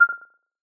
9. notification3